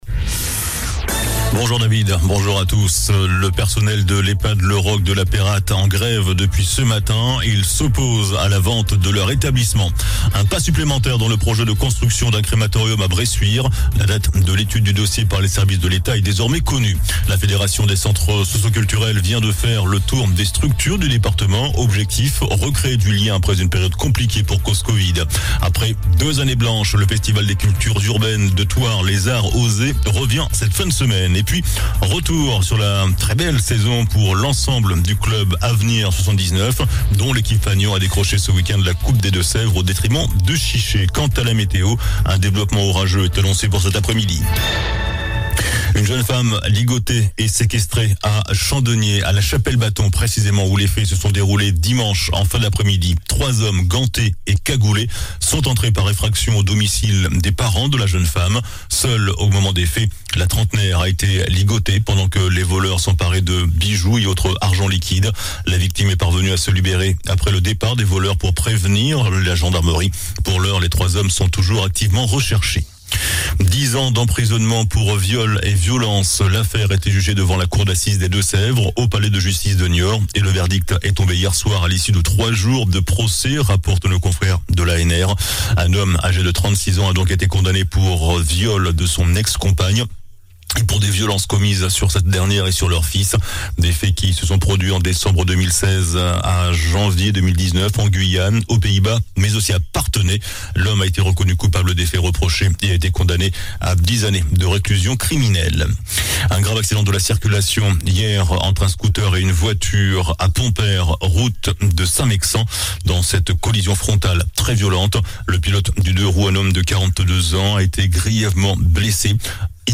JOURNAL DU MARDI 21 JUIN ( MIDI )